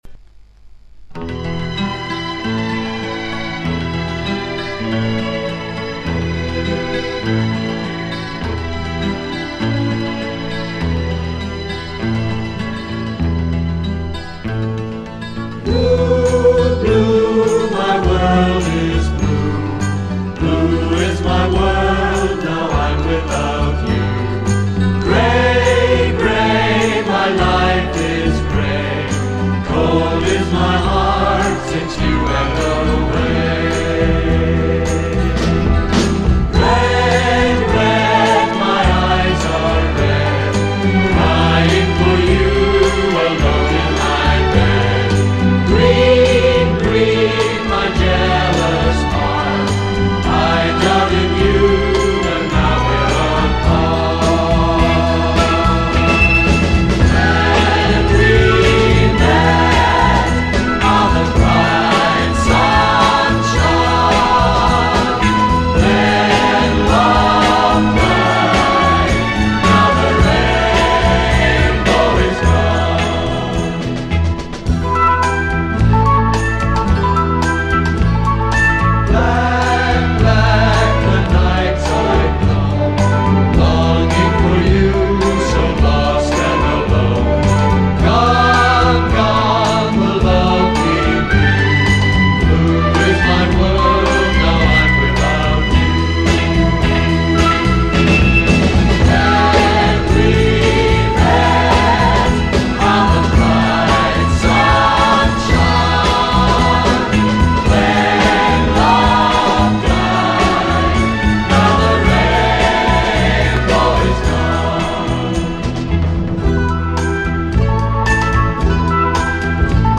Genre:Exotica